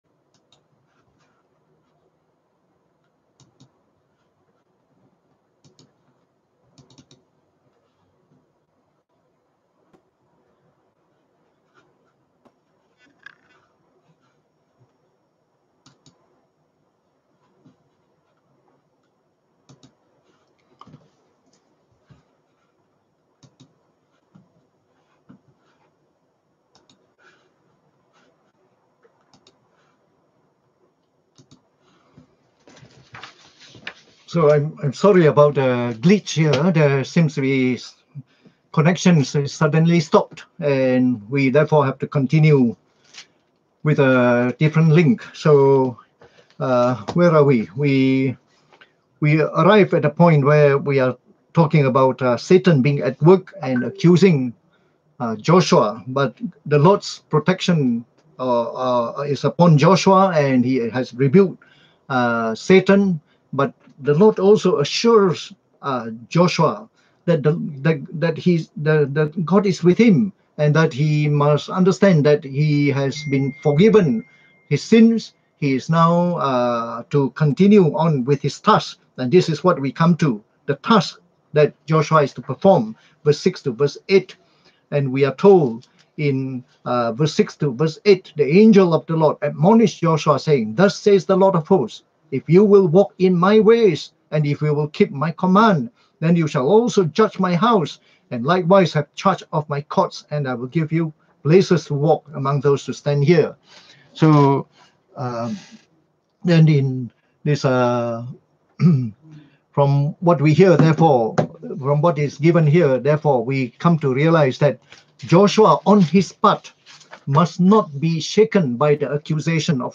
(There was an interuption on the talks so this had been Spilt into two parts)